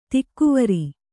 ♪ tikkuvari